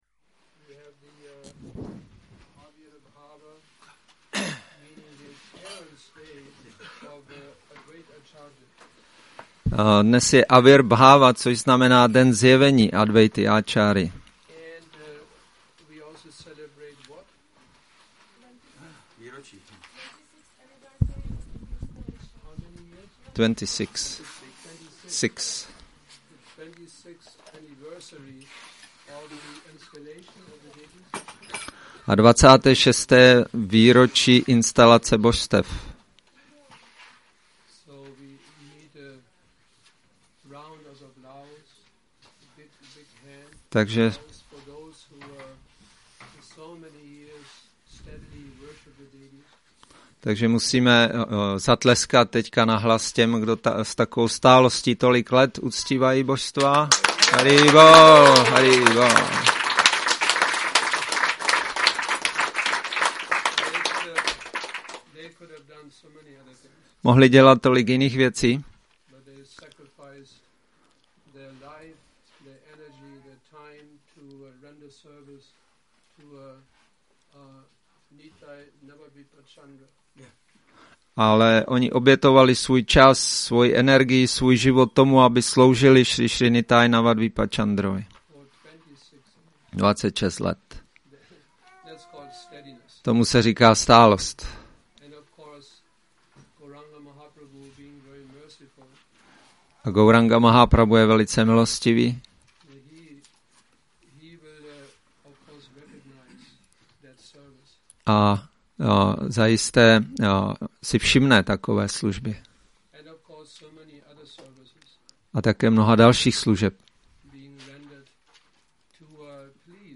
Přednáška